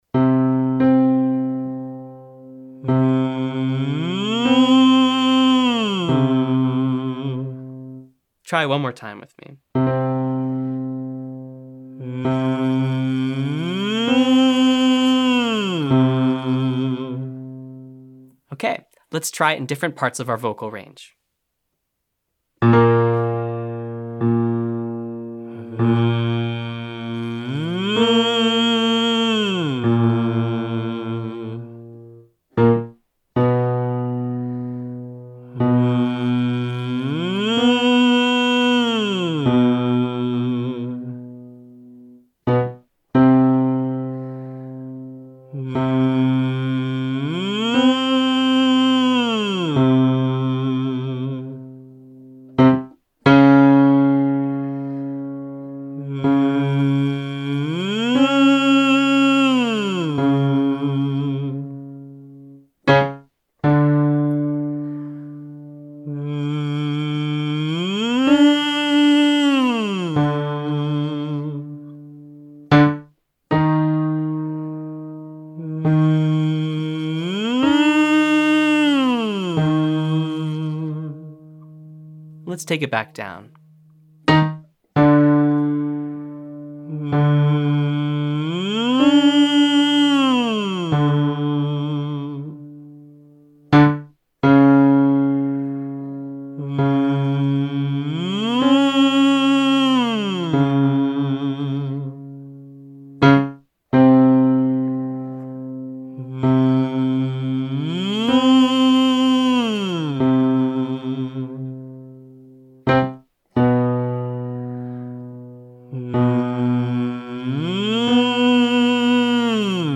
Day 2: SOVT's - Online Singing Lesson
Exercise 3: Straw Phonation 1-8-1